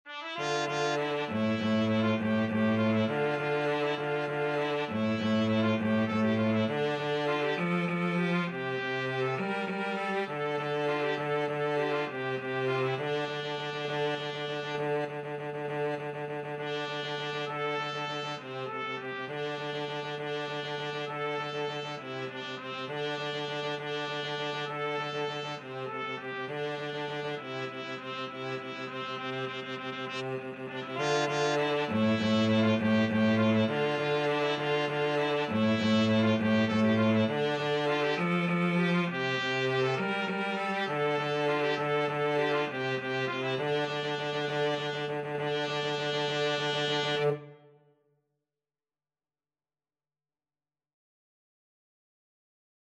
A film-score style piece
3/4 (View more 3/4 Music)
Fast and agressive =200
Film (View more Film Trumpet-Cello Duet Music)